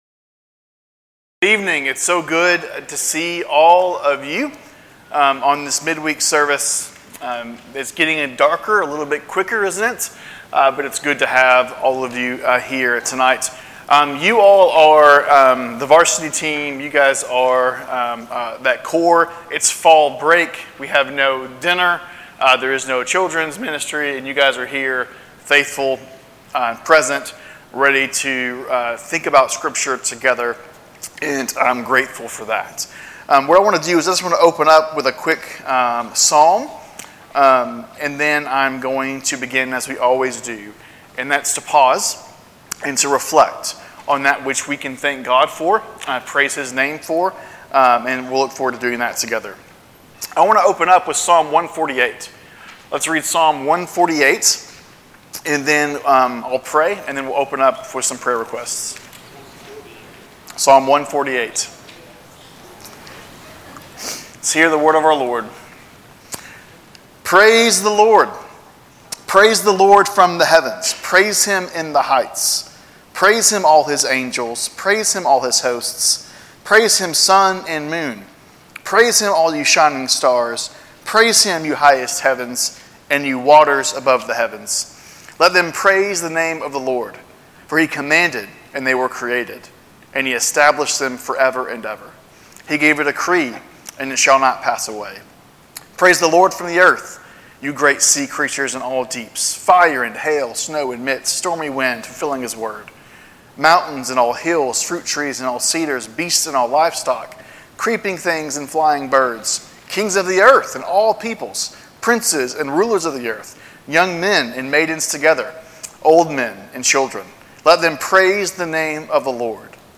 Wednesday Evening Bible Studies - 6:30pm